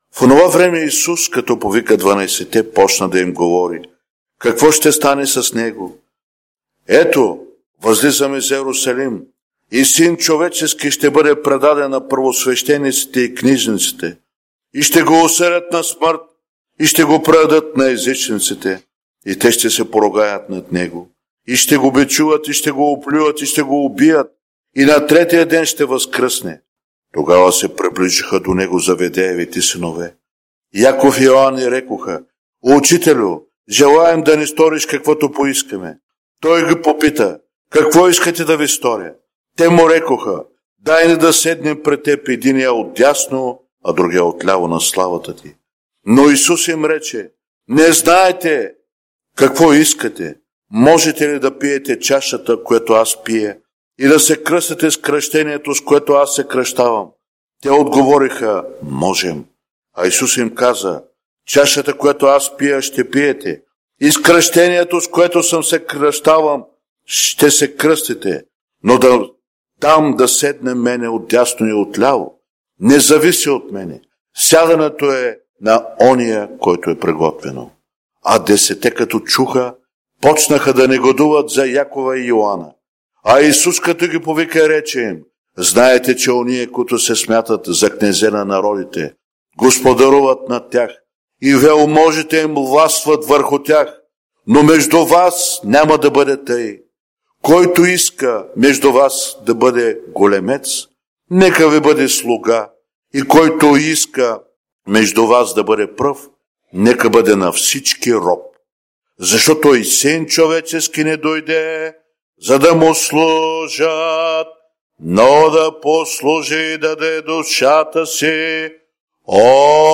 Евангелско четиво